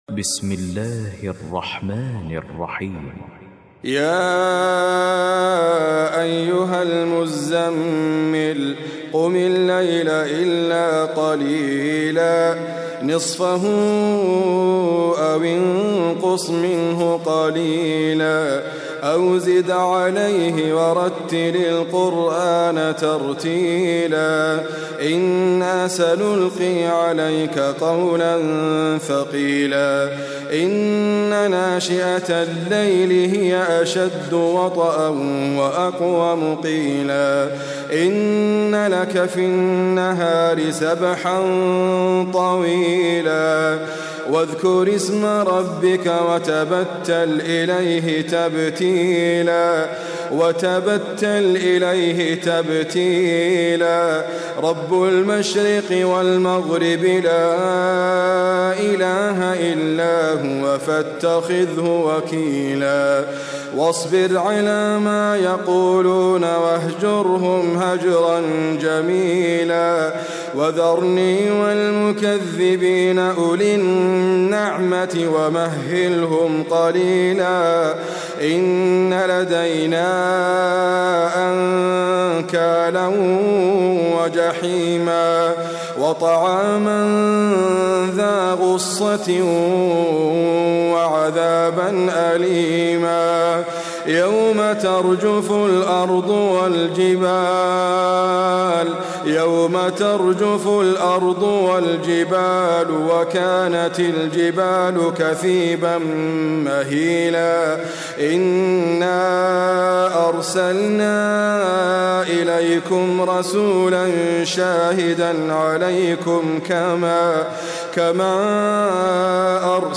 QuranicAudio is your source for high quality recitations of the Quran.